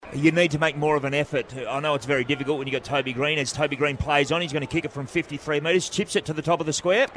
Peter Bell tries play by play commentary